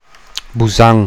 Standardladinische Form
[buˈzaŋ]
Fassaner Variante